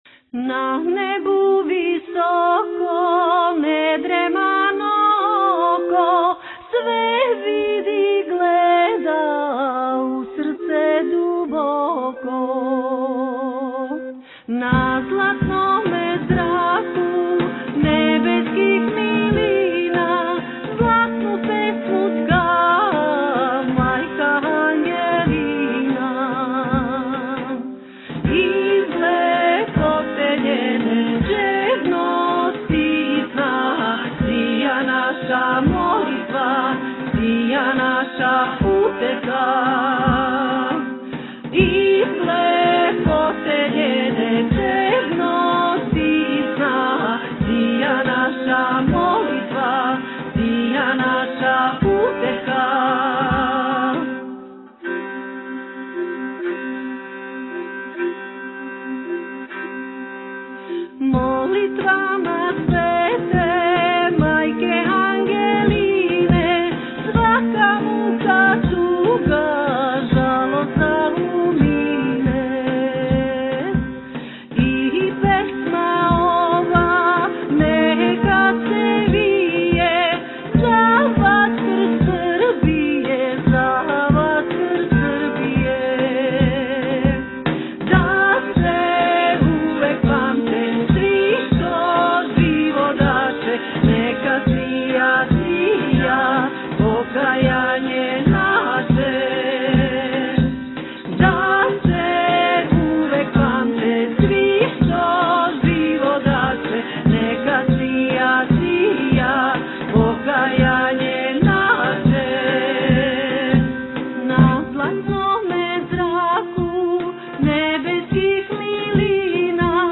На празник Преподобне мати Ангелине српске, истоимени црквени хор из Никшића прославио је крсну славу и двадесетогодишњицу од оснивања.